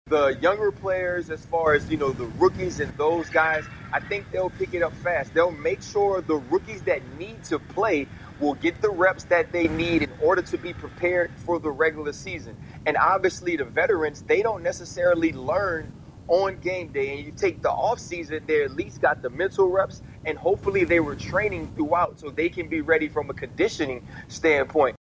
ESPN analyst Ryan Clark said the shorten short season impacts how teams manage players. He theorizes that rookies and younger players will get priority in reps.